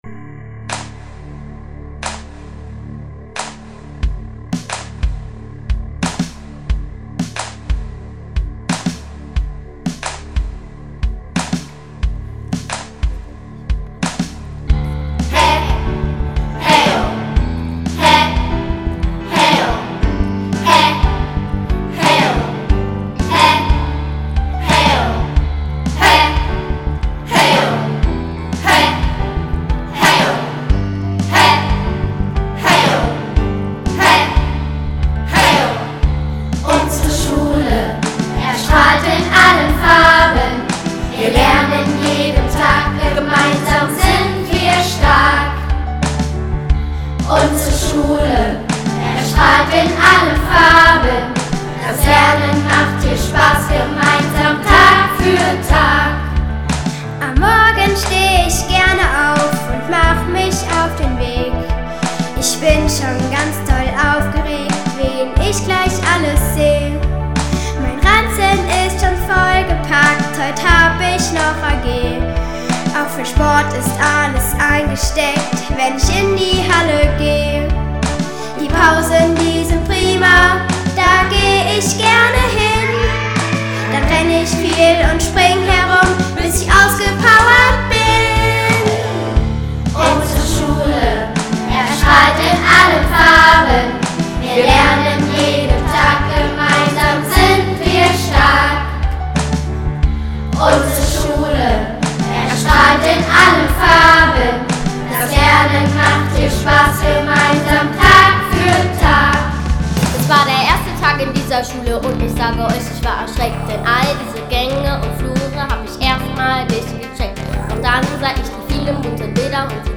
Text und Melodie: Thomas Lehmann
UnsereSchulemitKids.mp3